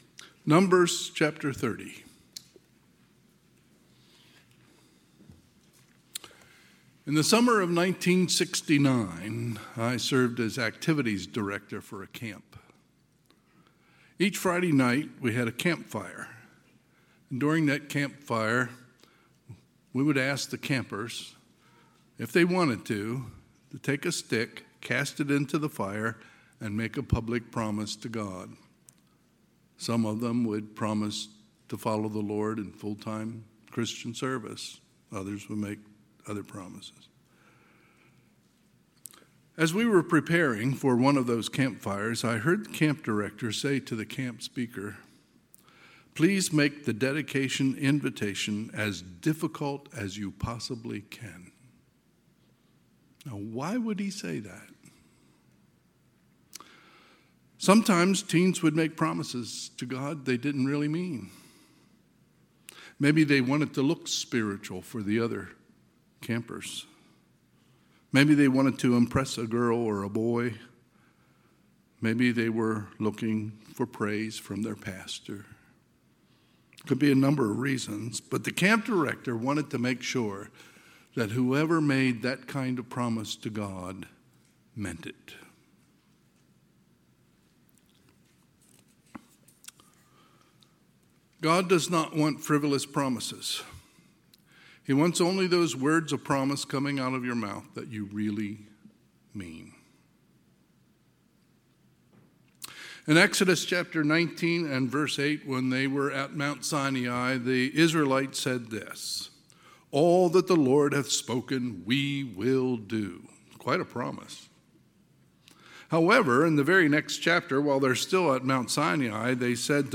Sunday, August 31, 2025 – Sunday PM
Sermons